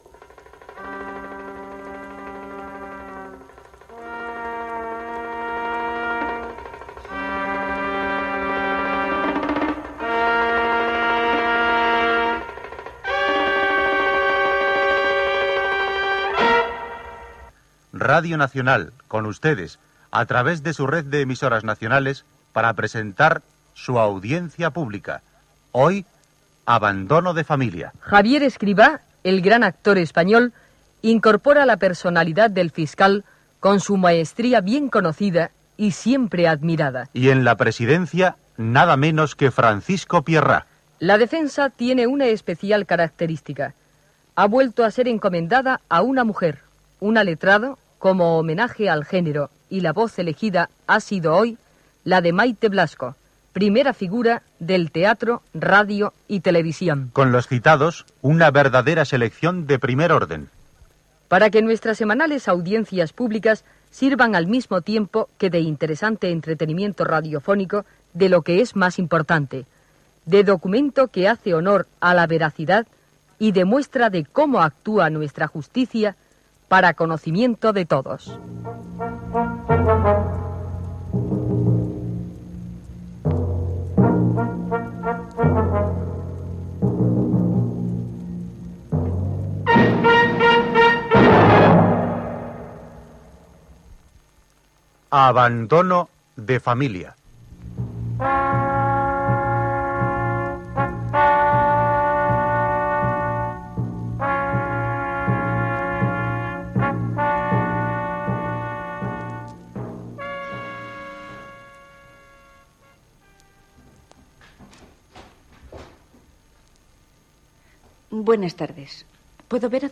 Ficció